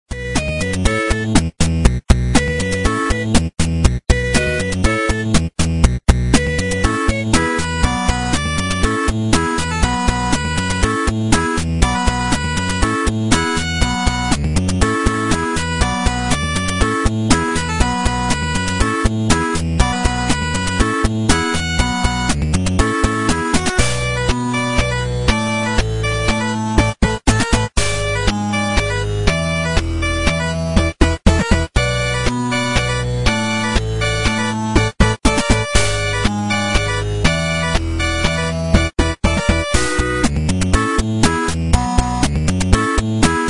como si fuera una melodía de videojuego de los años 90.